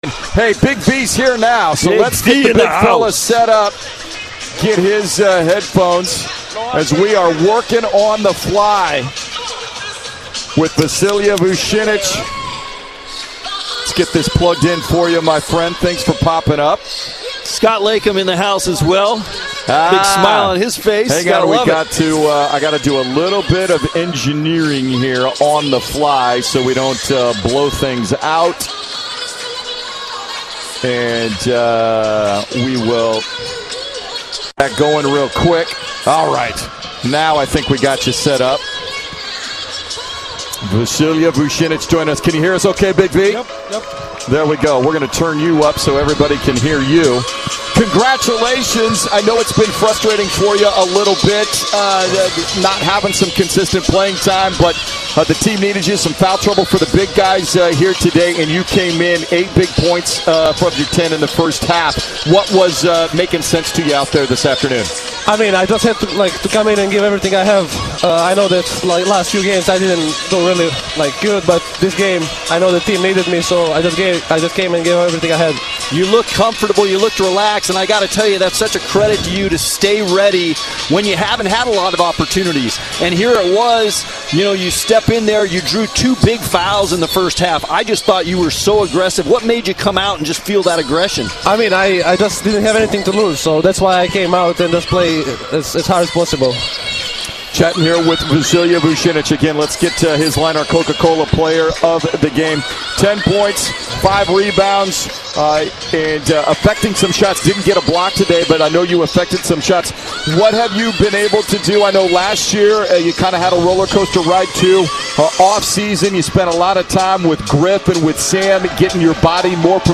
November 25, 2022 Post-game radio show from Portland's 83-71 victory over Villanova on Day 2 of the Phil Knight Invitational at Moda Center.